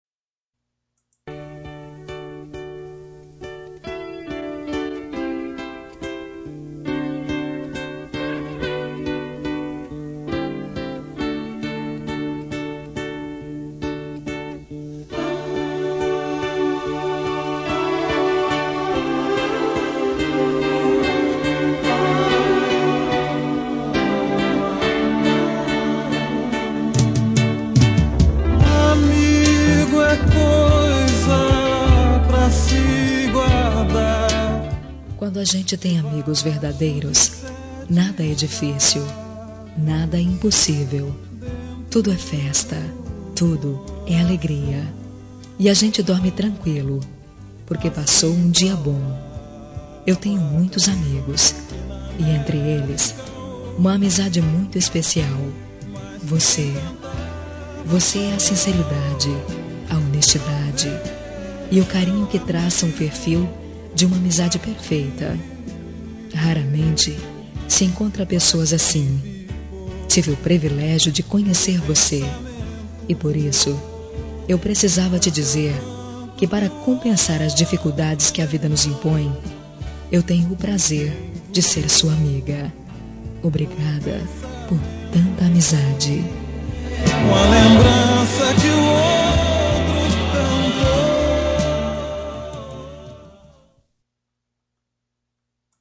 Telemensagem de Amizade – Voz Feminina – Cód: 96
96-amizade-fem.m4a